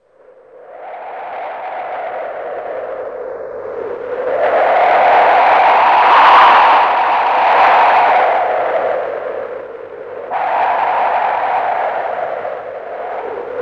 FX  WIND 07R.wav